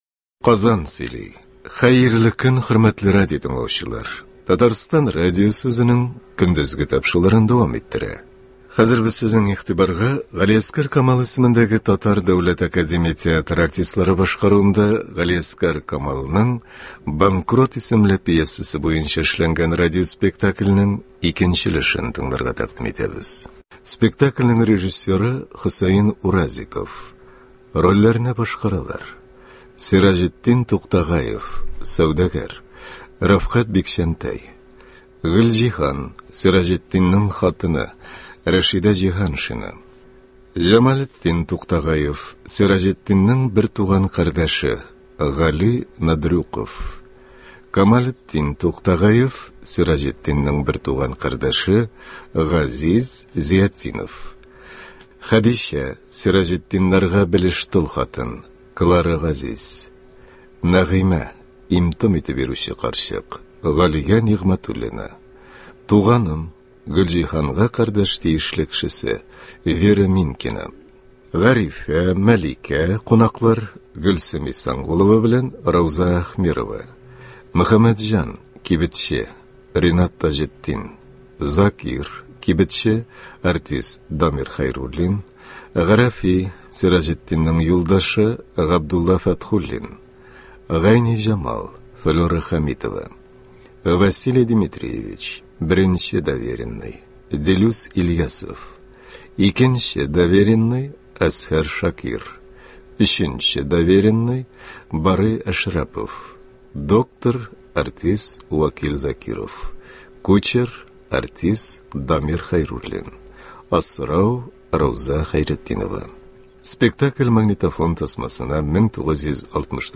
Г.Камал. “Банкрот”. Г.Камал ис. ТДАТ спектакле.